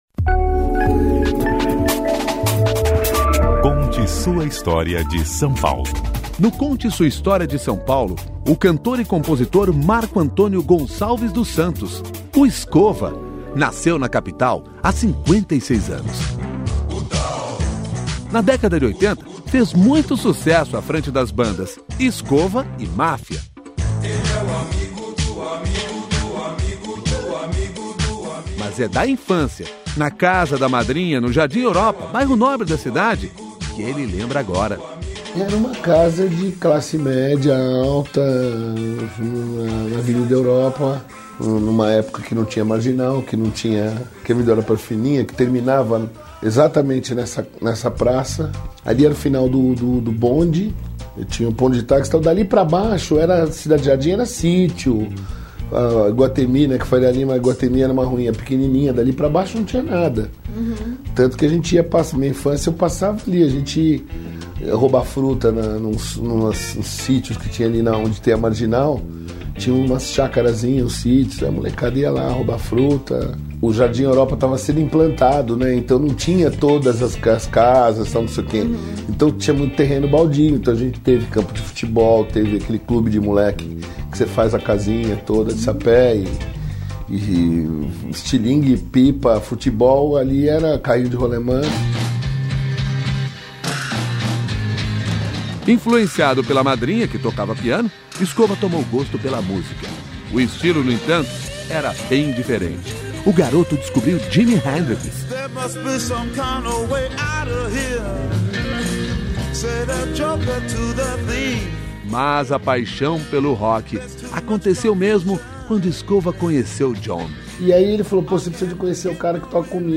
Neste depoimento gravado pelo Museu da Pessoa, Cunha Lima, ex-secretário de Cultura do Estado de São Paulo, lembra como foi a educação no colégio São Bento e curiosidades do Largo São Francisco.